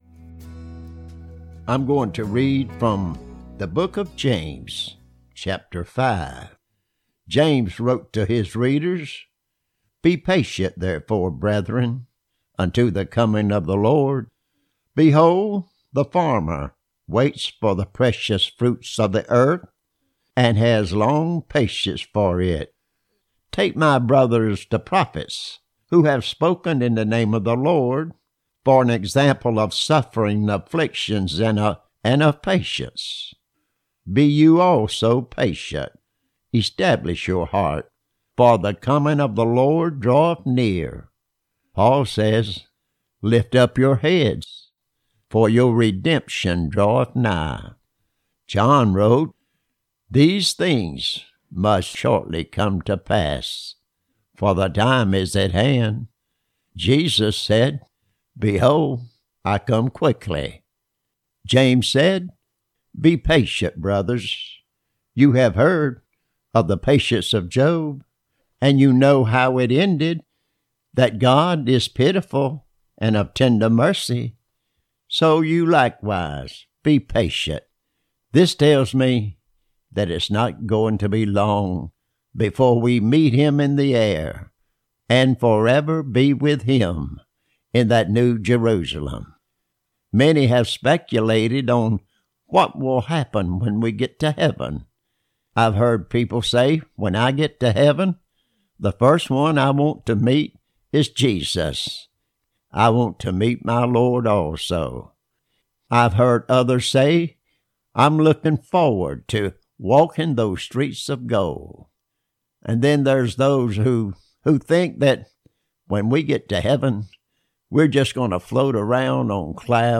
Lesson (4)